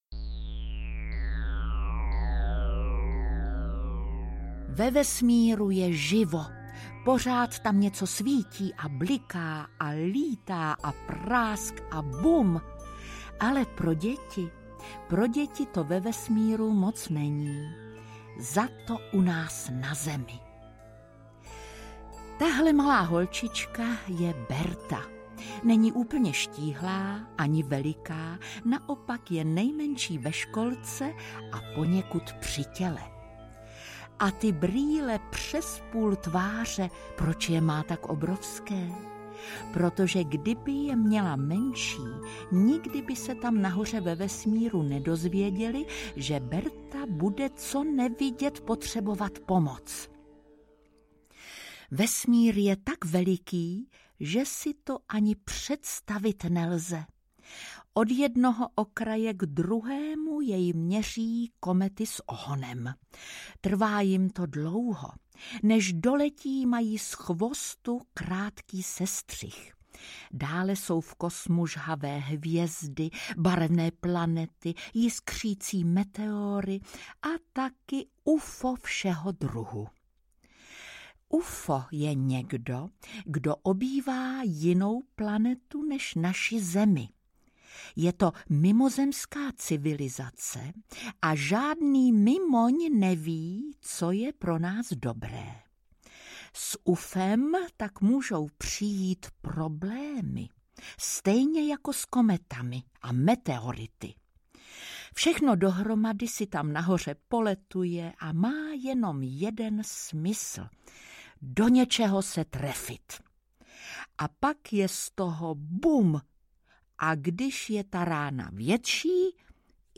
Berta a Ufo audiokniha
Ukázka z knihy
• InterpretJitka Molavcová